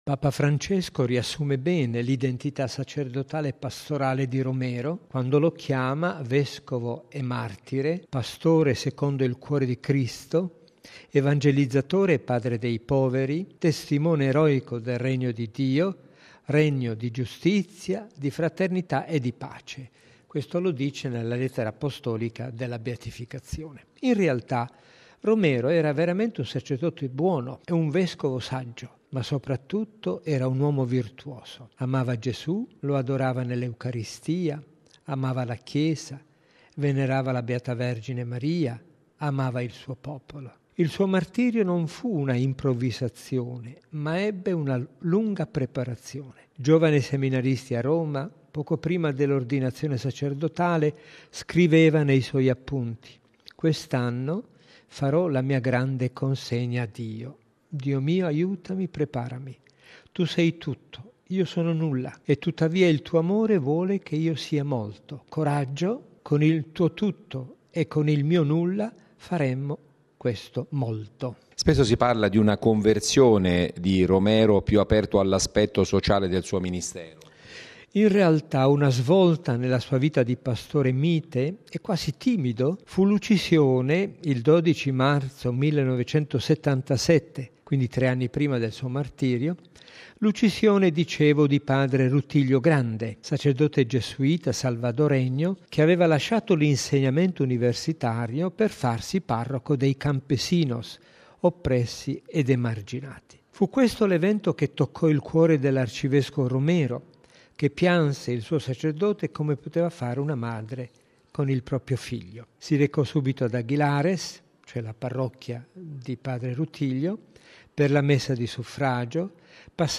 Domani a San Salvador, alle 18 ora italiana, nella Plaza del Divino Salvador del Mundo, sarà beatificato l’arcivescovo Oscar Arnulfo Romero Galdámez, assassinato in odio alla fede il 24 marzo 1980 da un killer degli squadrone della morte mentre celebrava la Santa Messa. A rappresentare Papa Francesco, il cardinale Angelo Amato, prefetto della Congregazione delle Cause dei Santi.